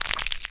Minecraft Version Minecraft Version latest Latest Release | Latest Snapshot latest / assets / minecraft / sounds / block / frogspawn / hatch3.ogg Compare With Compare With Latest Release | Latest Snapshot
hatch3.ogg